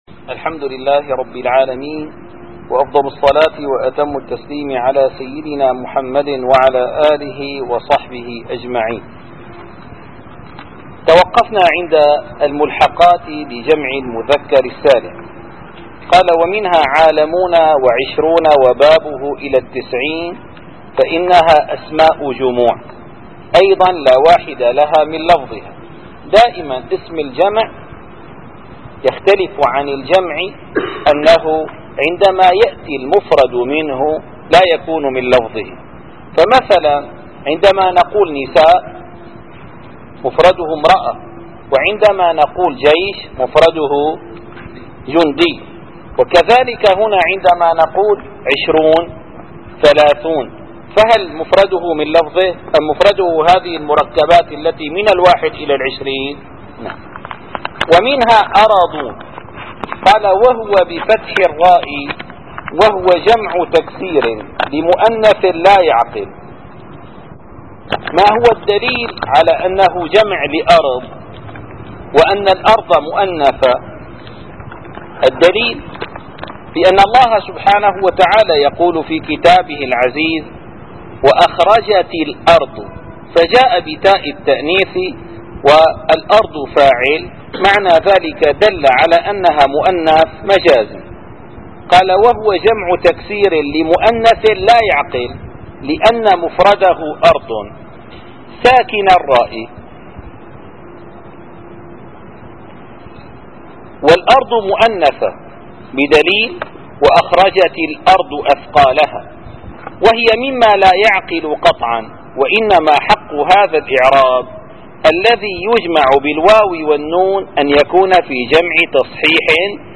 - الدروس العلمية - شذور الذهب في معرفة كلام العرب - ومنها عالمون وعشرون ص88-97.